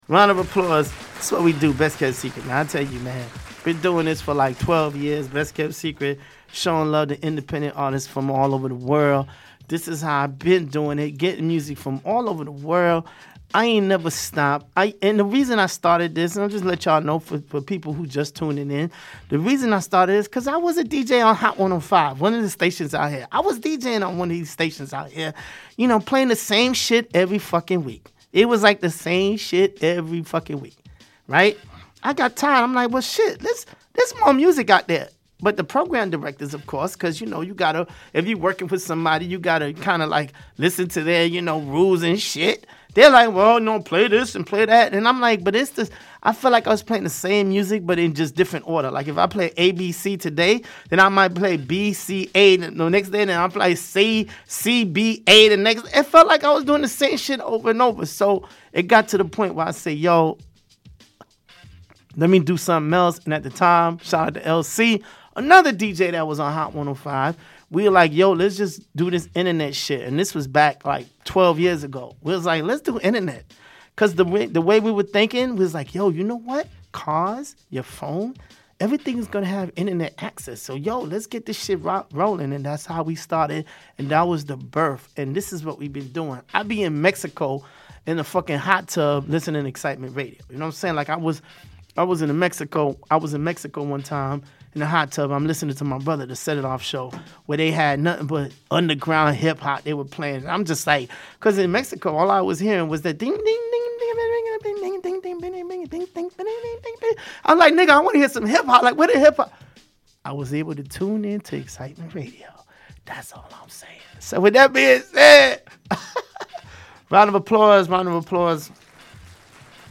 Showcasing Independent Artist from all over the world. We have live interviews and give feedback on your music.